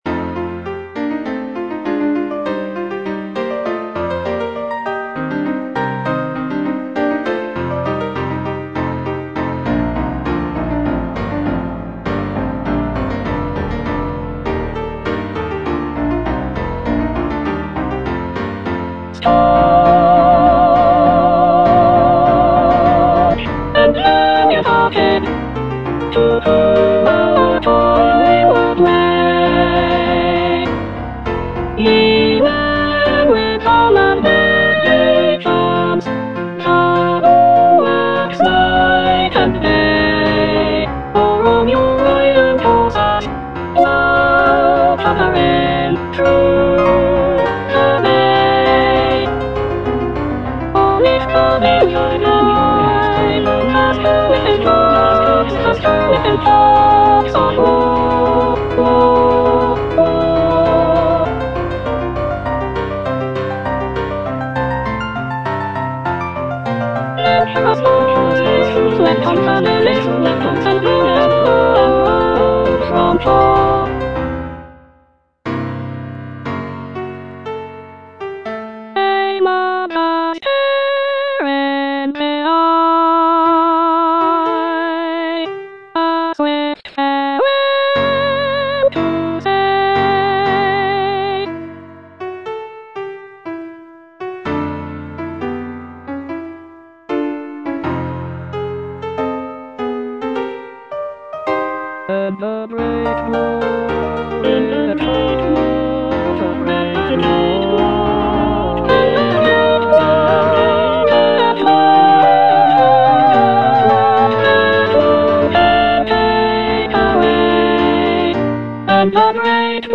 C.H.H. PARRY - THE CHIVALRY OF THE SEA Staunch and valiant-hearted (soprano I) (Emphasised voice and other voices) Ads stop: auto-stop
"The Chivalry of the Sea" is a choral work composed by C.H.H. Parry.
With its soaring melodies and powerful harmonies, the music captures the spirit of heroism and pays homage to those serving at sea.